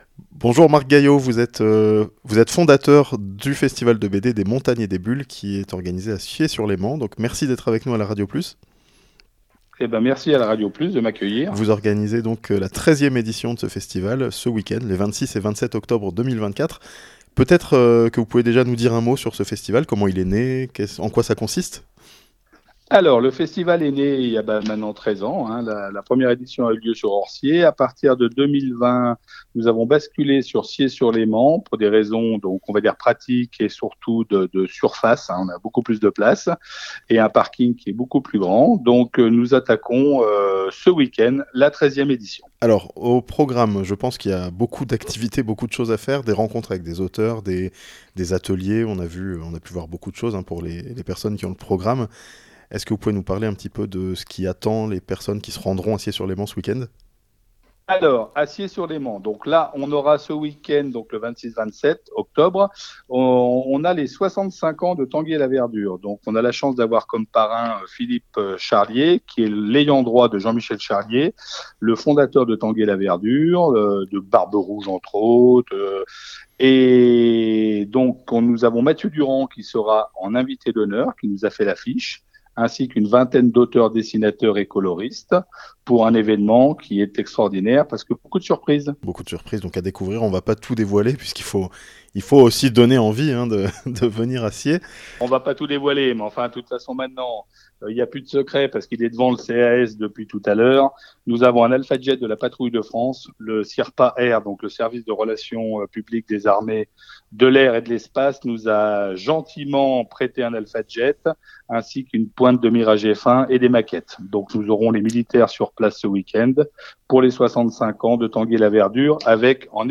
Ce weekend, Sciez-sur-Léman fête la bande dessinée (interview)